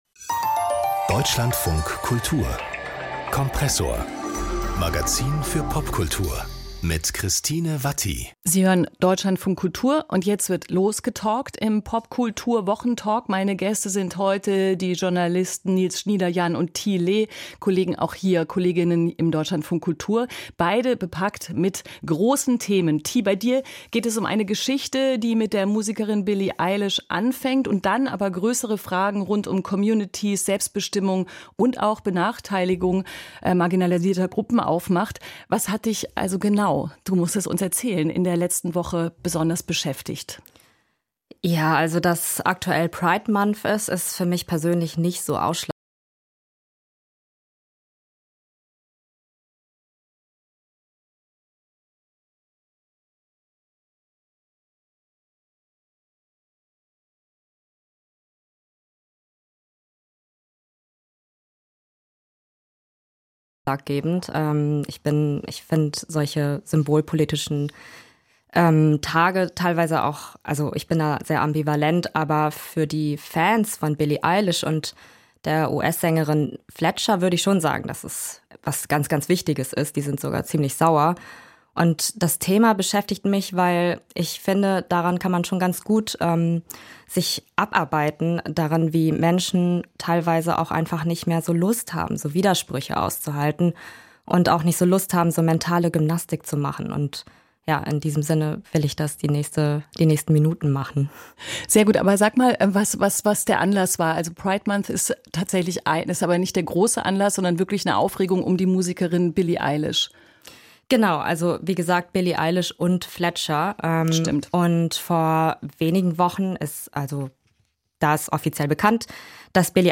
Debatte ums Dating